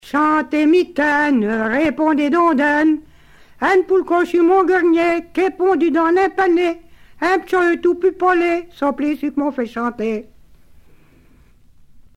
enfantine : berceuse
Genre brève